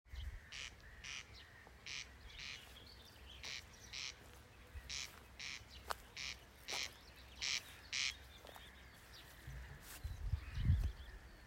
коростель, Crex crex
Ziņotāja saglabāts vietas nosaukumspļava
Skaits2
СтатусПоёт